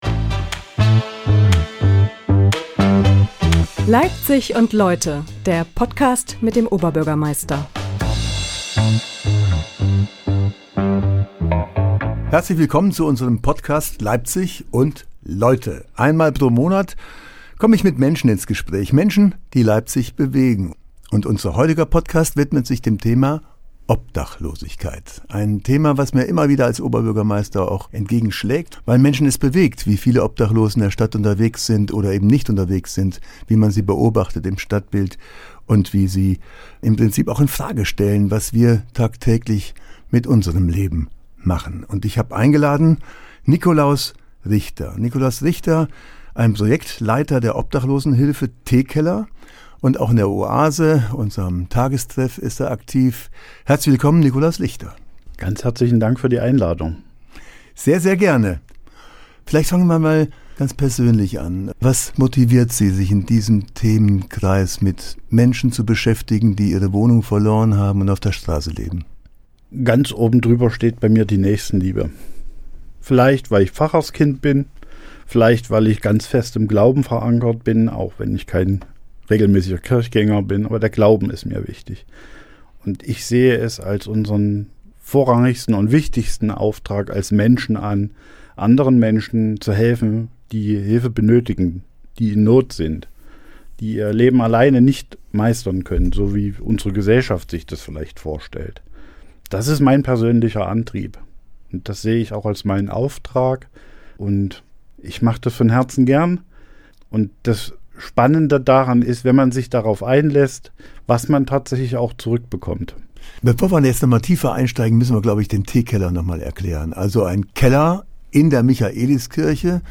Wie gehen wir mit Menschen um, die das Hilfesystem nicht nutzen können oder wollen? Ein Gespräch über Menschlichkeit in Zeiten wachsender sozialer Ungleichheit.